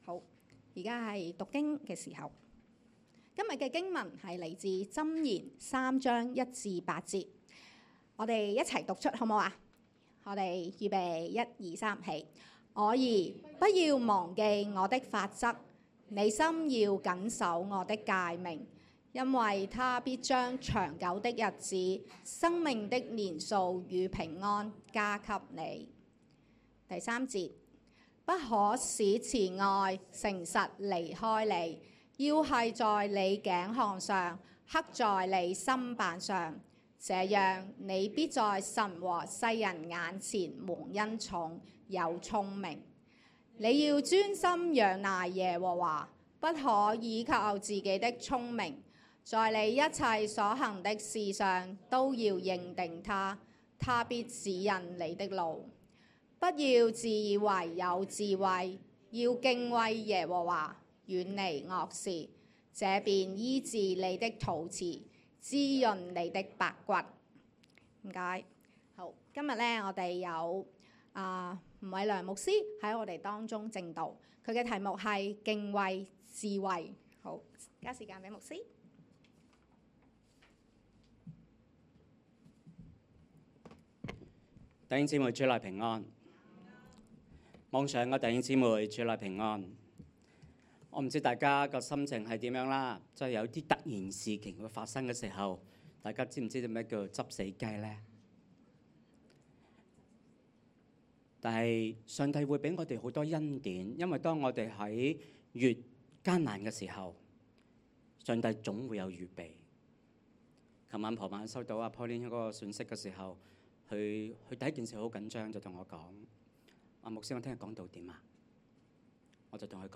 sermon_2025_1221.mp3